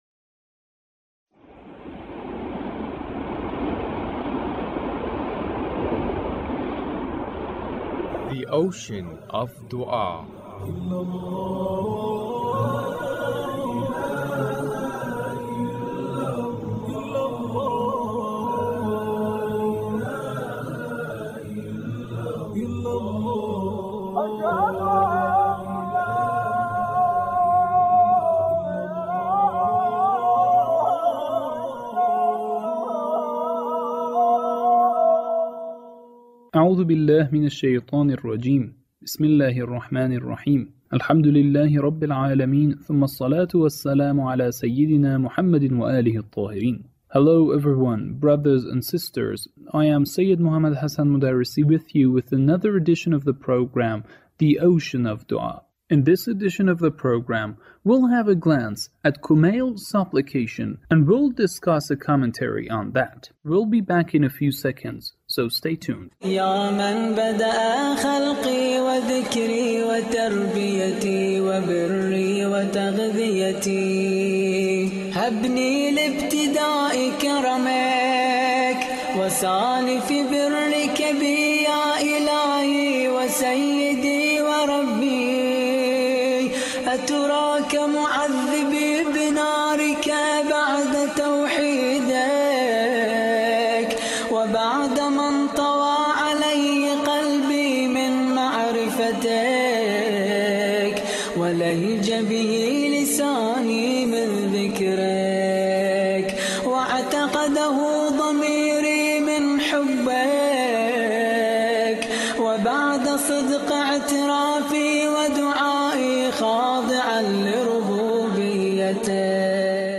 The Leader's speech on The Demise Anniversary of The Imam